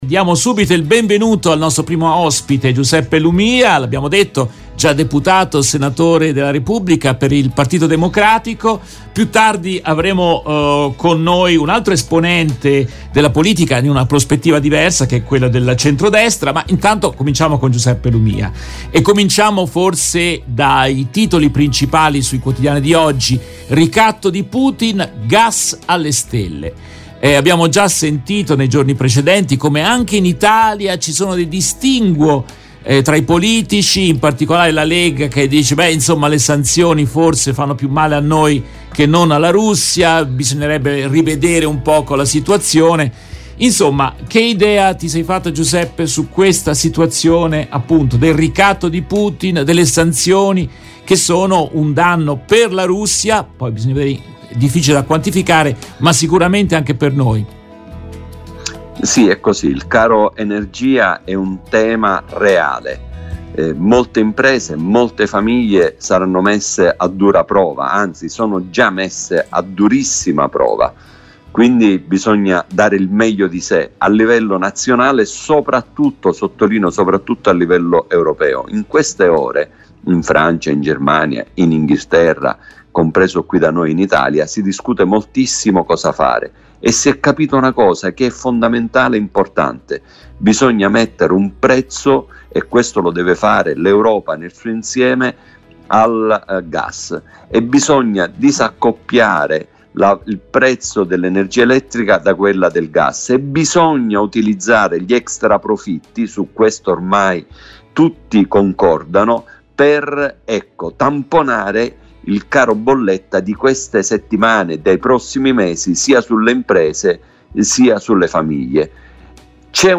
In questa trasmissione in diretta del 06 settembre 2022